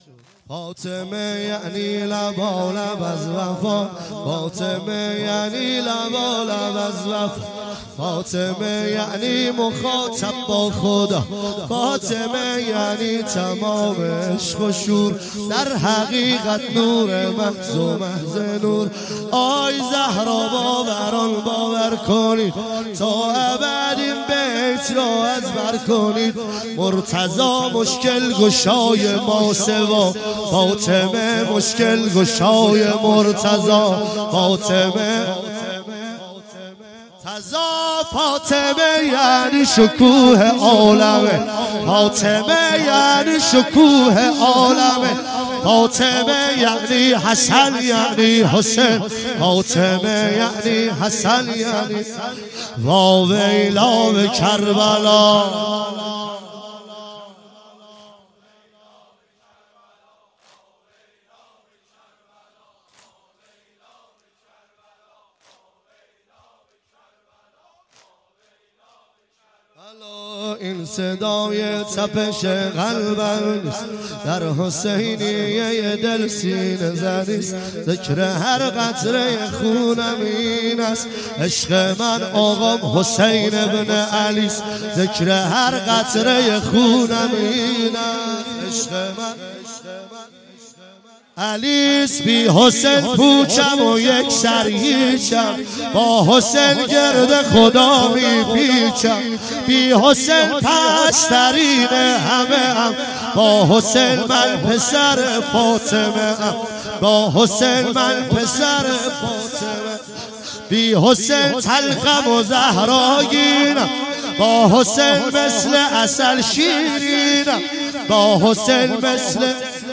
فاطمیه
واحد مداحی